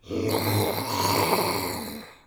Zombie Voice Pack - Free / Zombie Growl
zombie_growl_010.wav